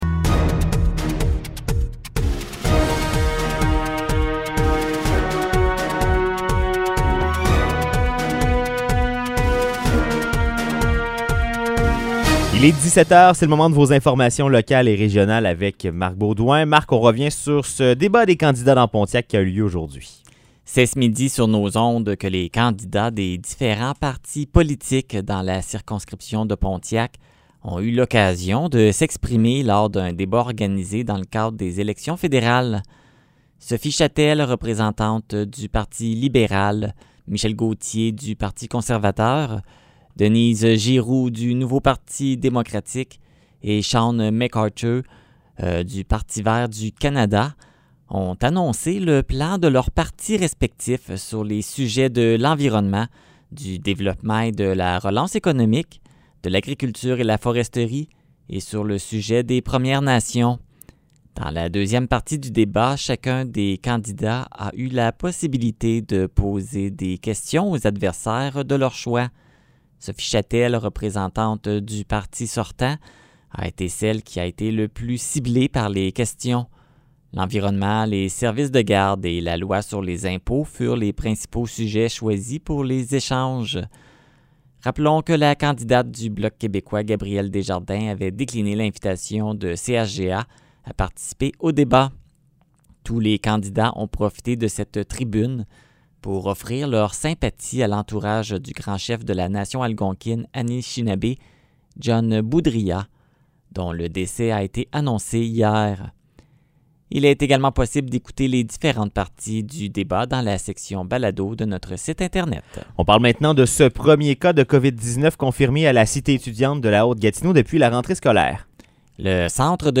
Nouvelles locales - 9 septembre 2021 - 17 h